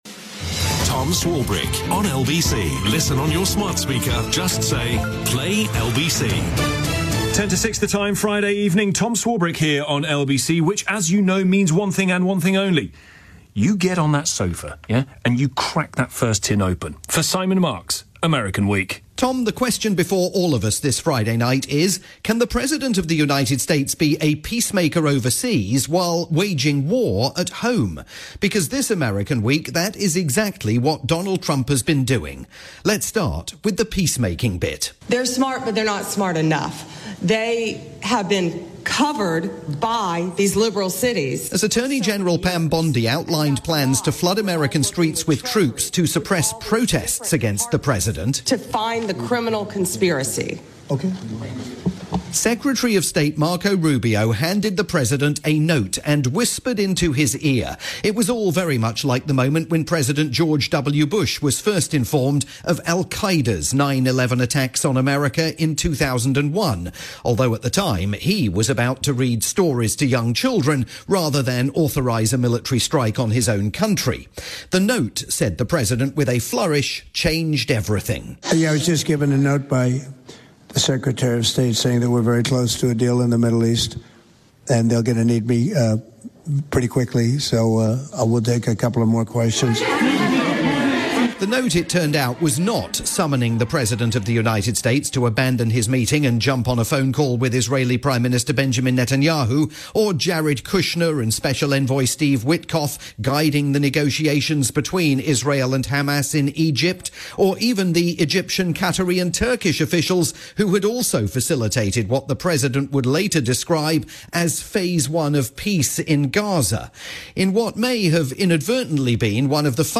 Friday drivetime programme on the UK's LBC